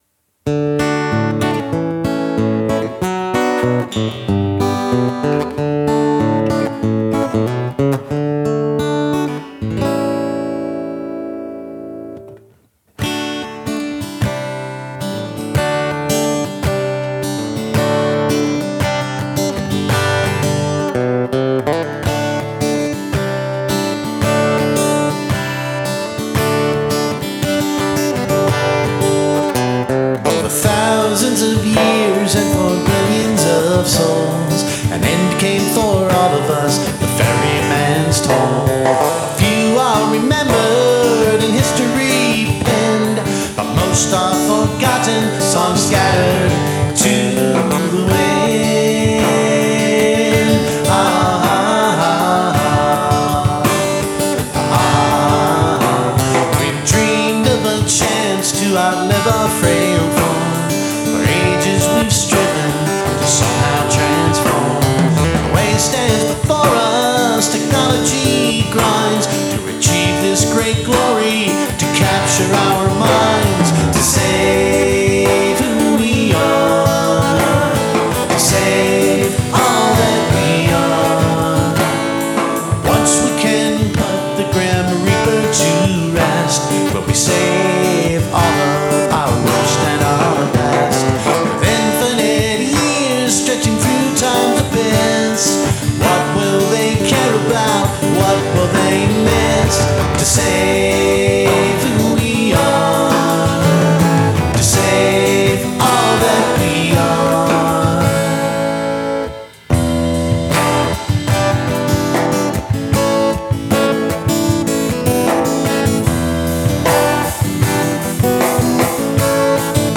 (3/4) D C4 D D4-D C4 D-|| C4|||-| G-||| /B /C e (x2)